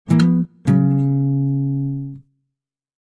descargar sonido mp3 guitarra a 9